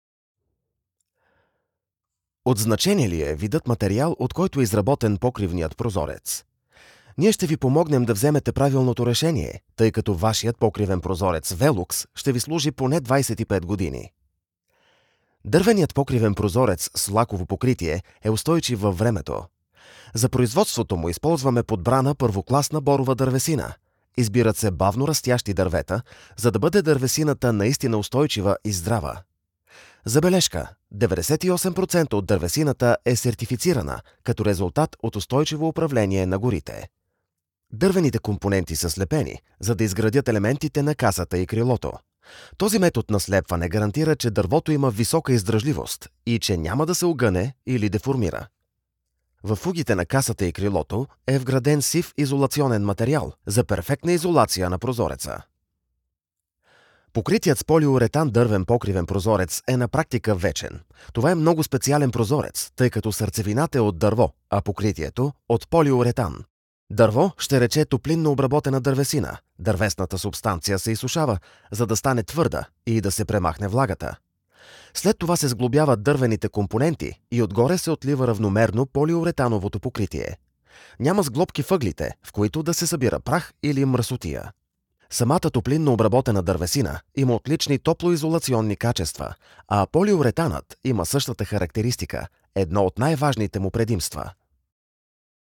Commercial, Natural, Cool, Warm, Corporate
Audio guide